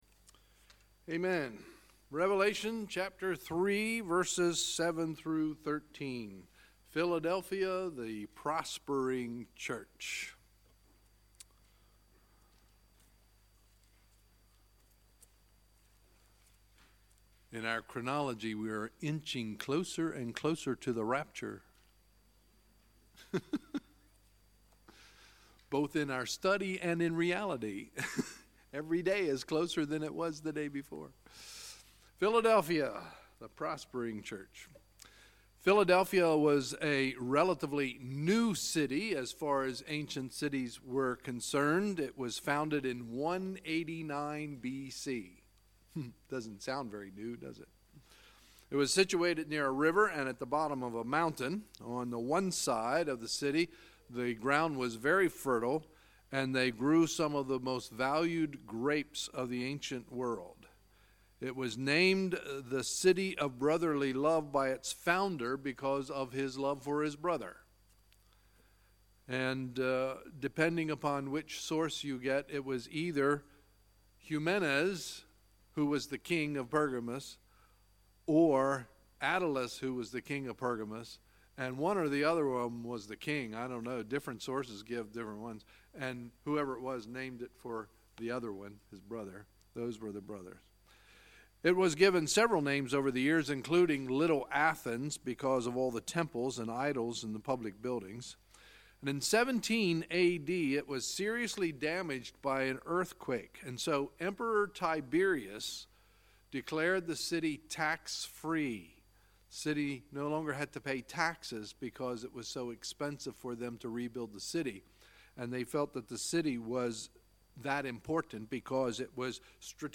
Sunday, April 8, 2018 – Sunday Evening Service